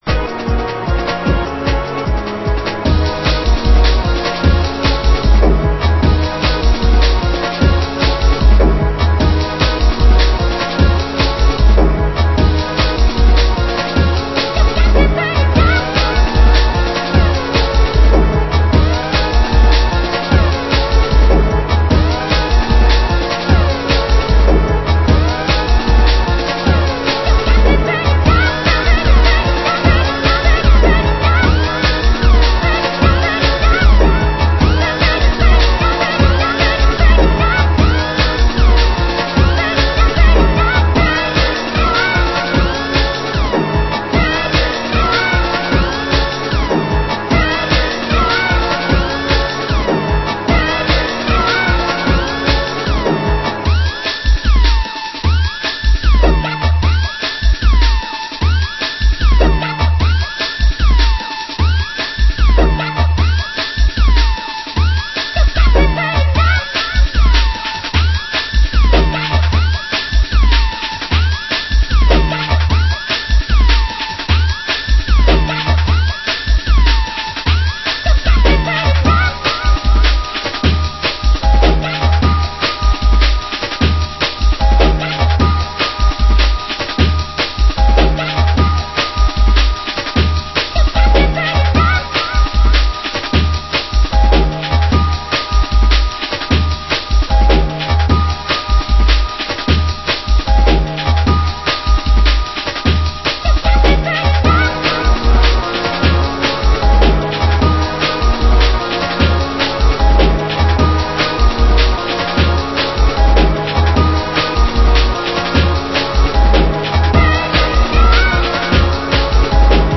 Genre: Jungle